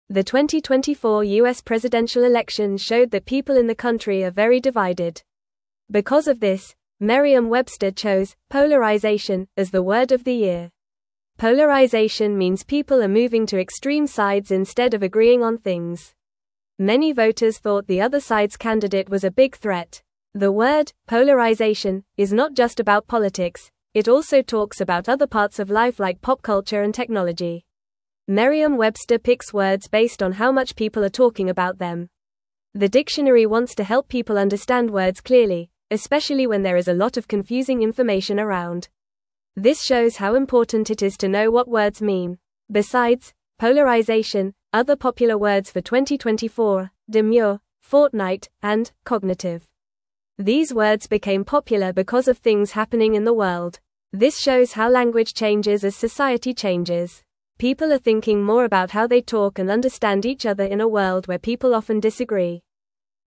Fast
English-Newsroom-Lower-Intermediate-FAST-Reading-People-Are-Divided-The-Word-of-the-Year.mp3